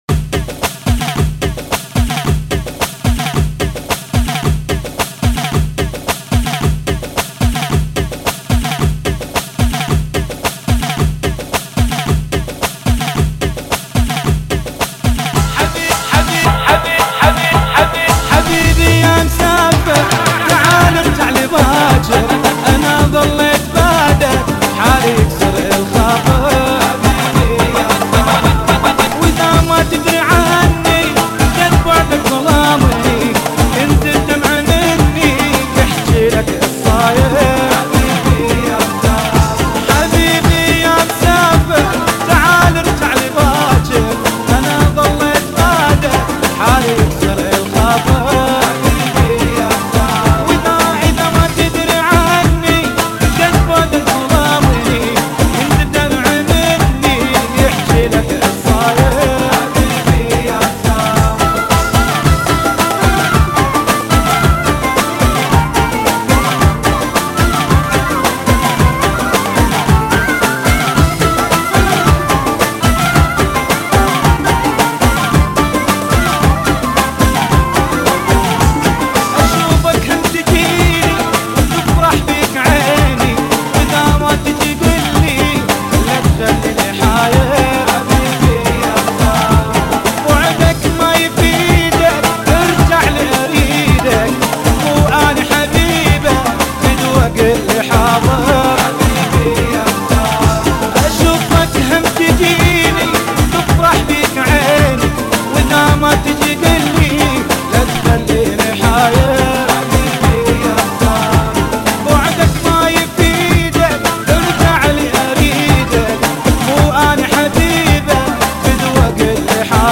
[ 110 Bpm ]